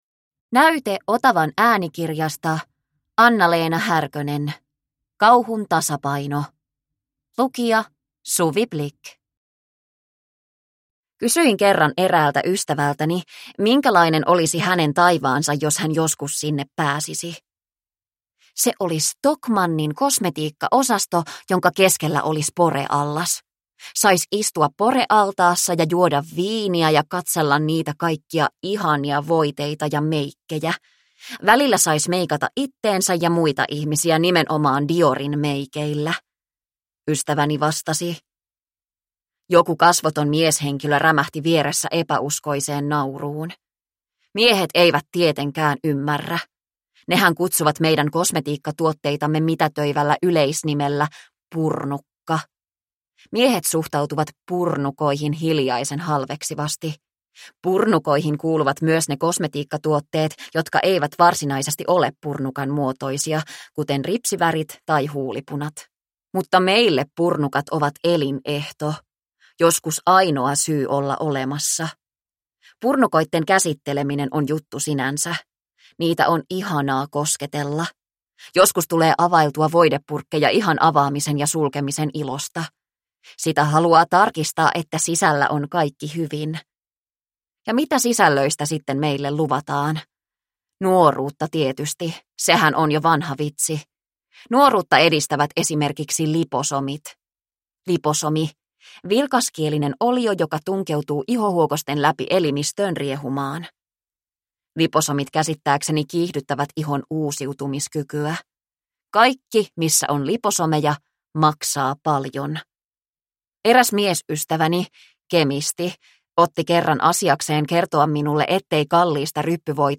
Kauhun tasapaino ja muita kirjoituksia – Ljudbok – Laddas ner